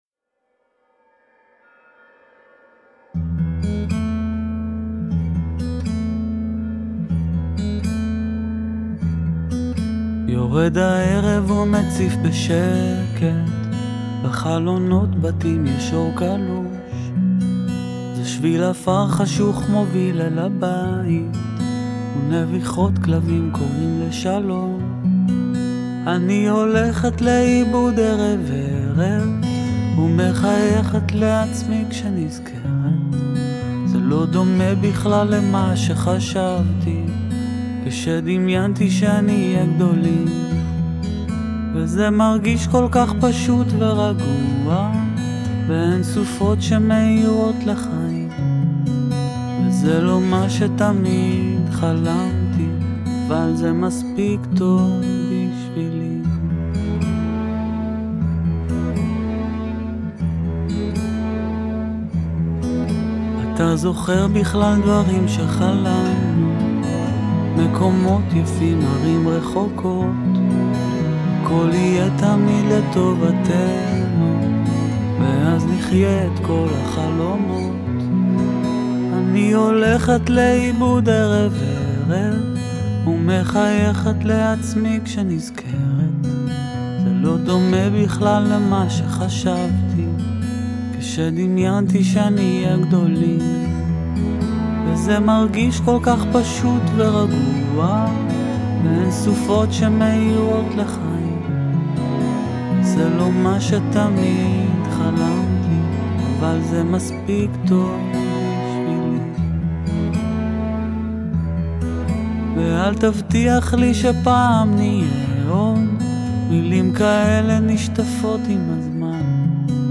Genre: Worl Music, Israel, Pop, Folk